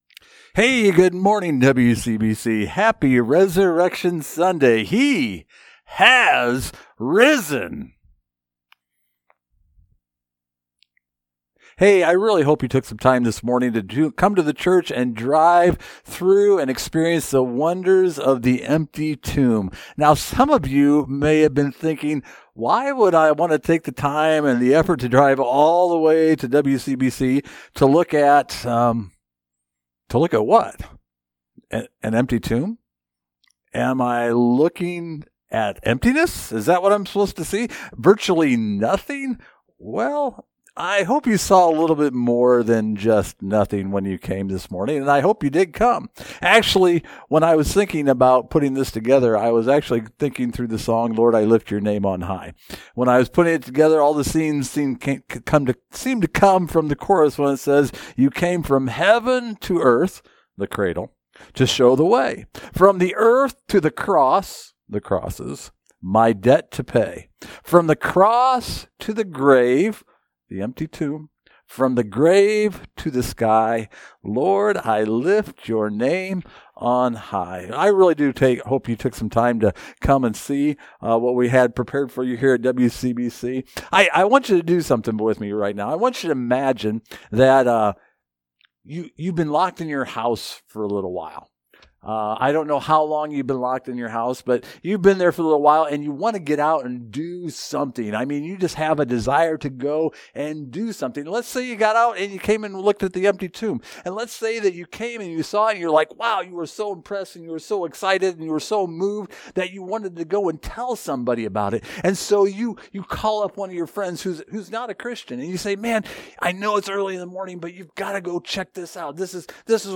Service Type: Online Message